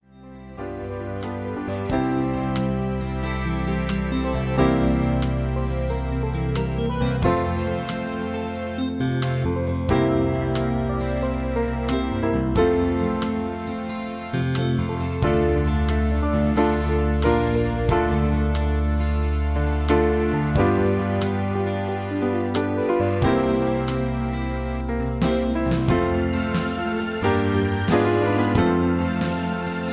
Tags: backing tracks , irish songs , karaoke , sound tracks